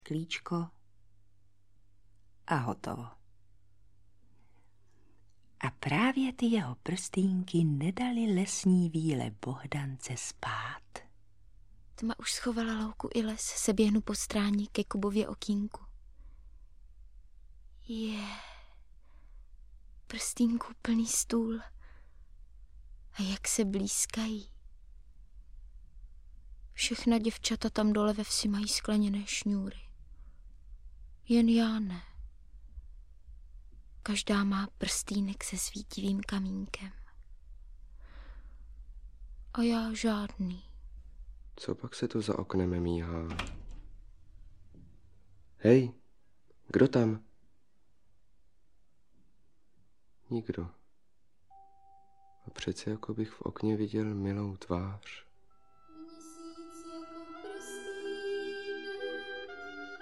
Audiobook
Read: Jan Kanyza